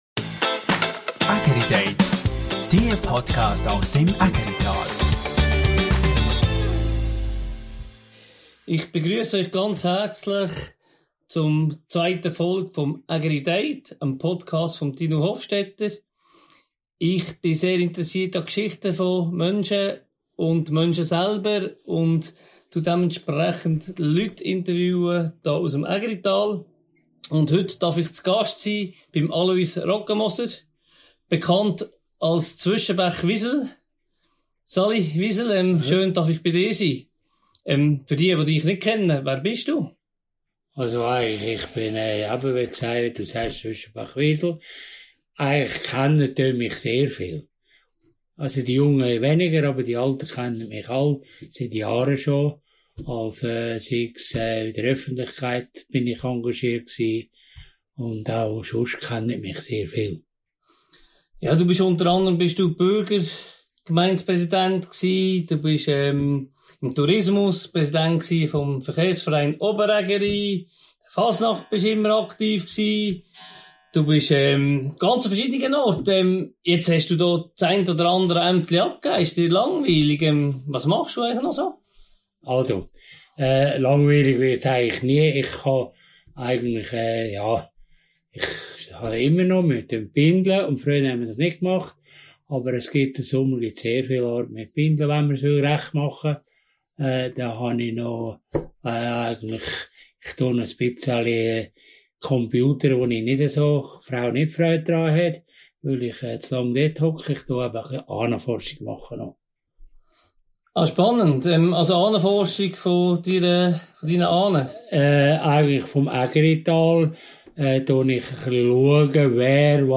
Im Gespräch wird die Entwicklung von Oberägeri angesprochen und ob das Pflegeheim vom Breiten ins Dorfzentrum verlegt werden soll. Ein Gespräch voller Anekdoten aus Oberägeri und klaren Worten zur Zukunft des Ägeritals.